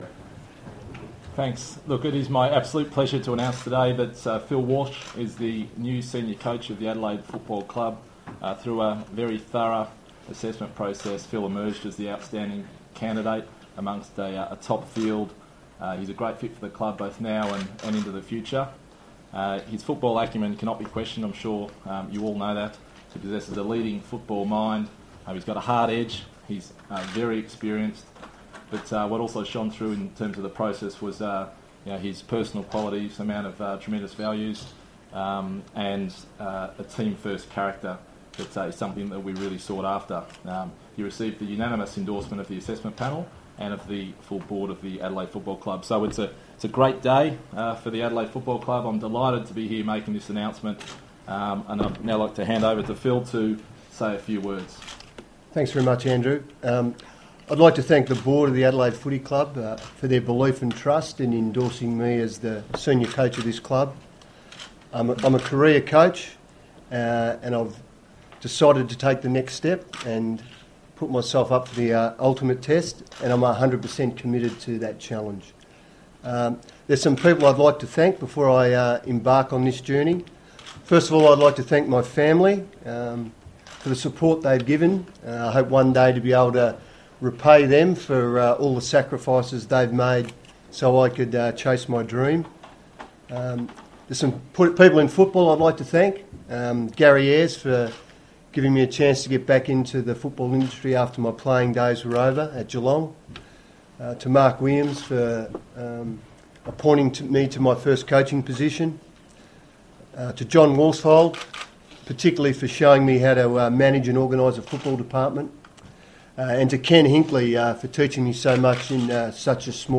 Phil Walsh Press Conference